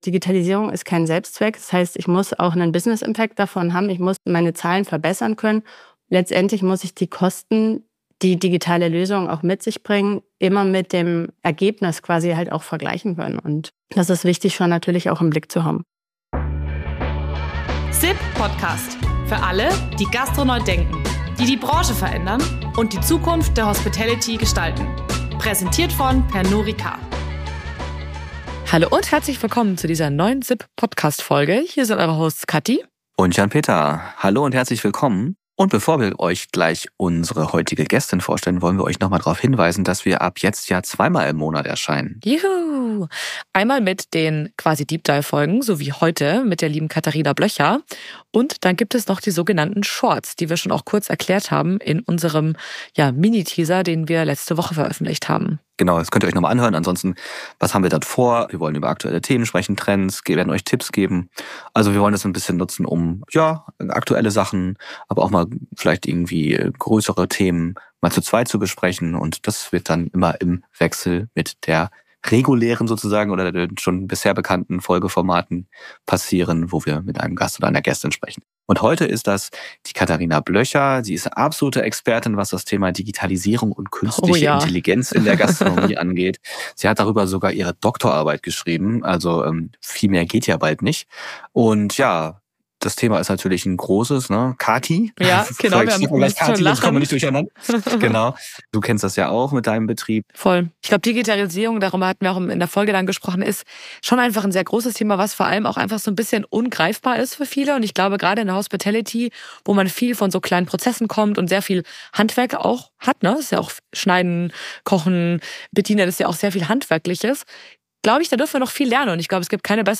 Freut euch auf ein lockeres und zugleich sehr informatives Gespräch über Tools und Trends, die Zukunft der Branche – und was regenerative KI damit zu tun hat.